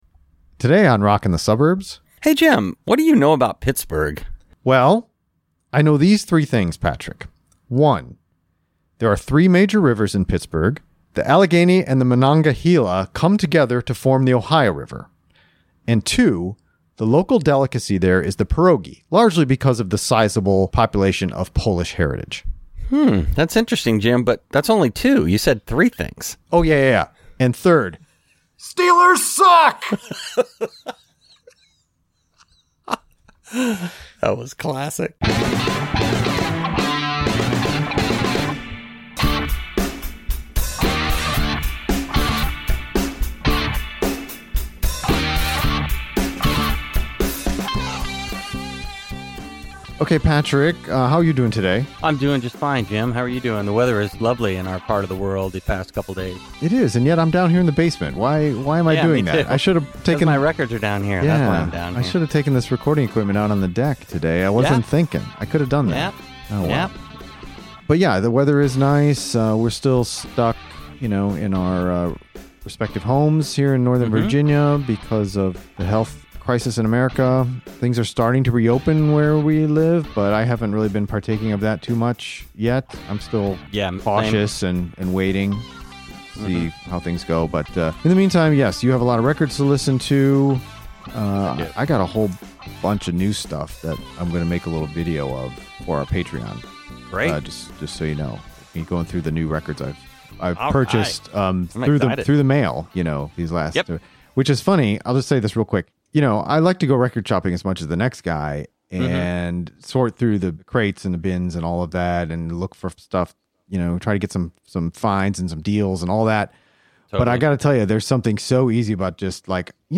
We even play a full song, "It's Only You," at the end of the episode.